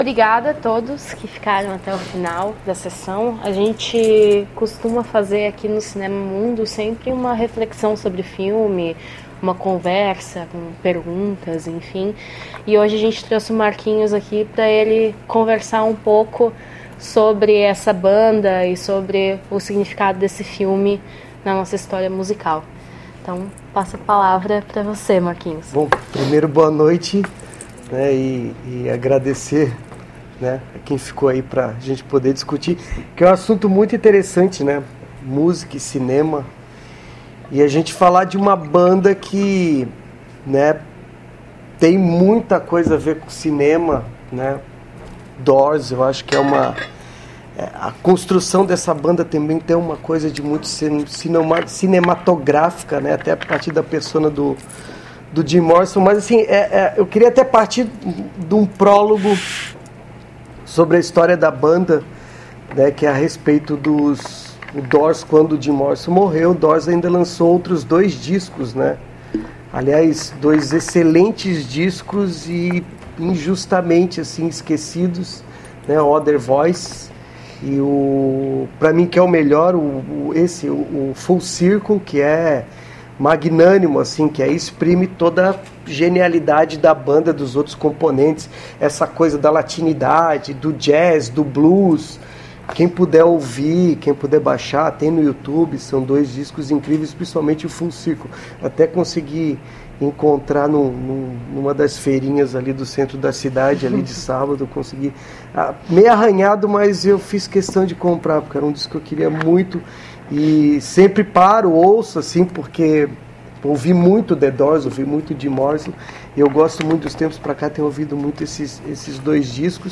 Comentários das sessões